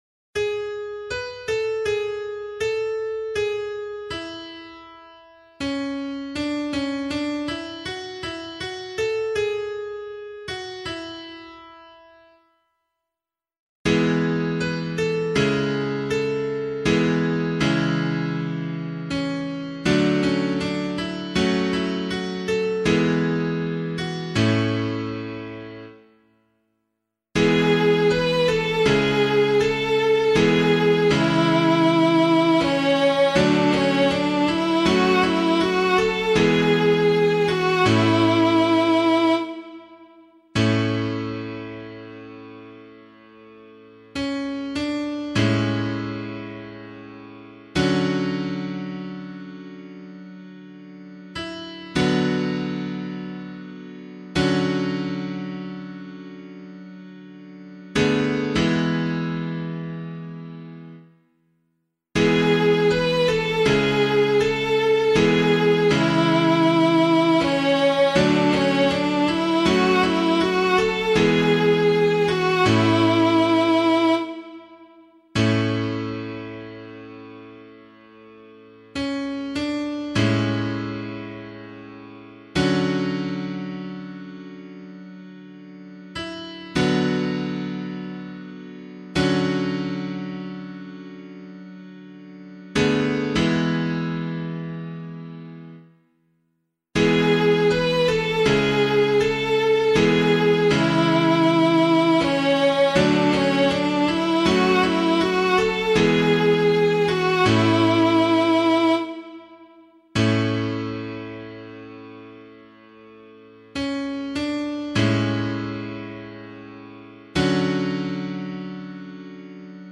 pianovocal
034 Corpus Christi Psalm C [APC - LiturgyShare + Meinrad 8] - piano.mp3